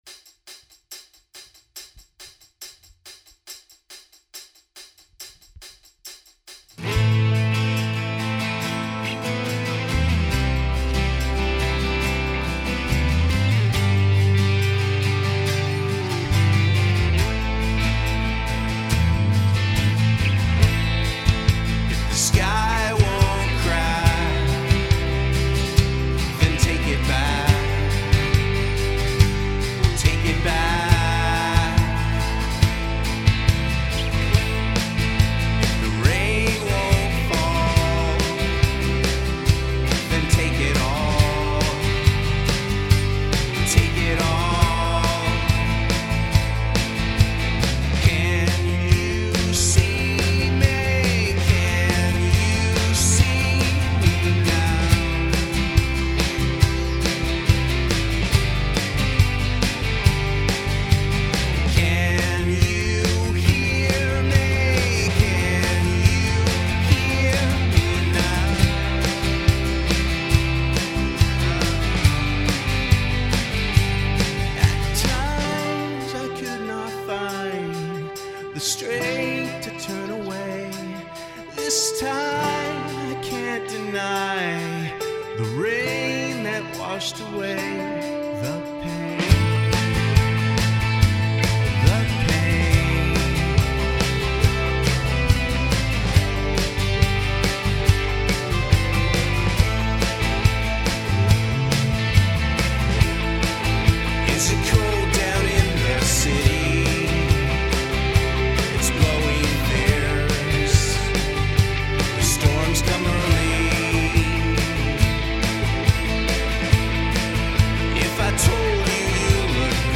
Keep in mind that 1) this is not a final mix; 2) vocals are a one take scratch that will be redone (pitchy, effects are off, etc.).